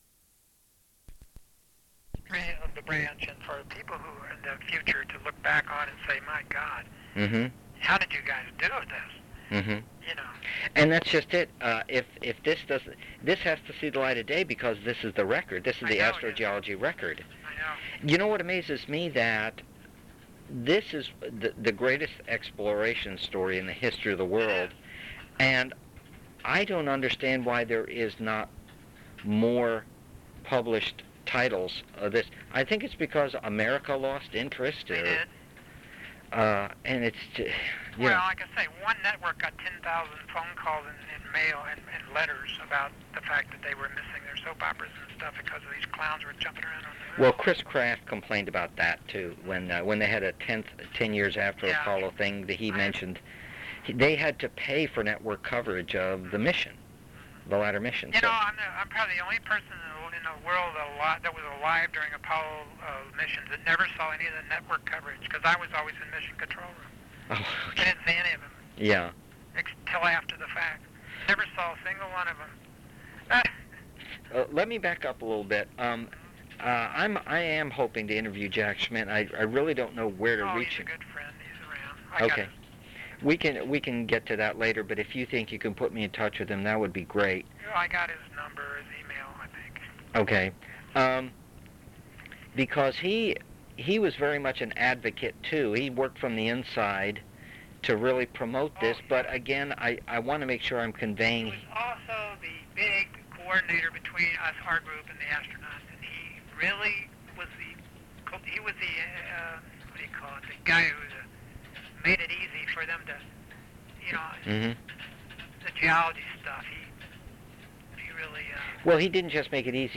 Type Interviews Audio
Tags Oral History